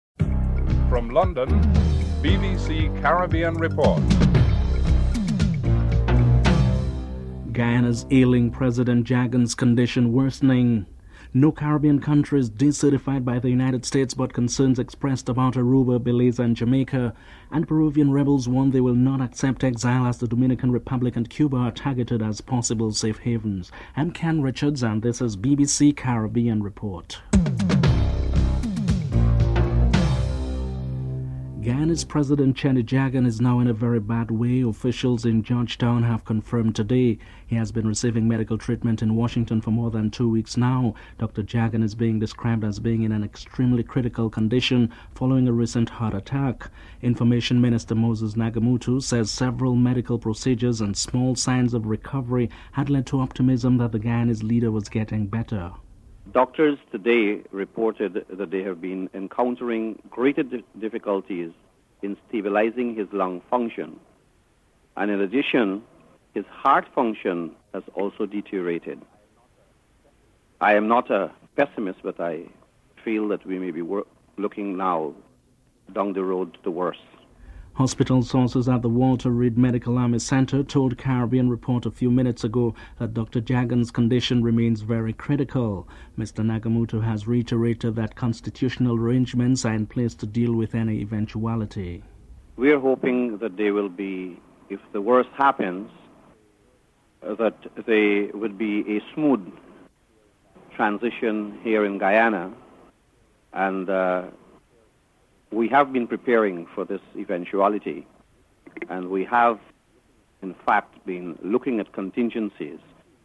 1. Headlines (00:00-00:31)
4. Richard Clarke, a visiting envoy to the Caribbean region is interviewed.